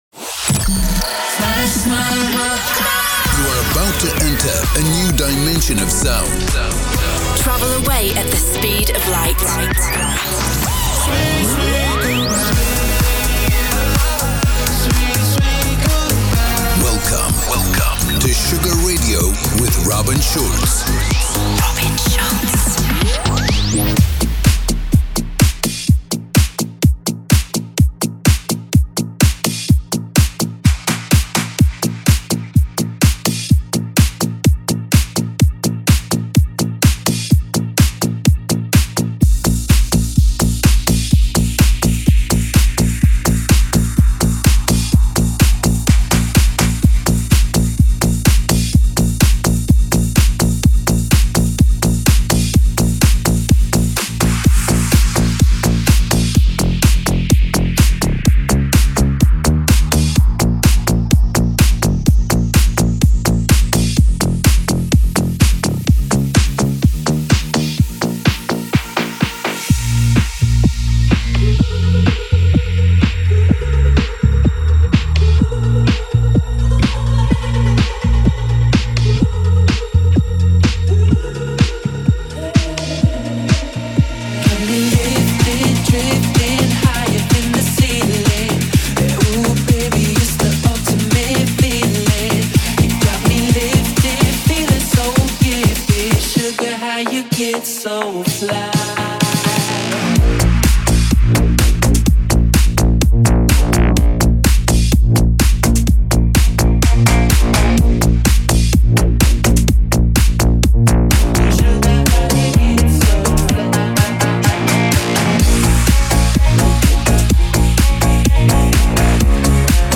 music DJ Mix in MP3 format
Genre: Electro Pop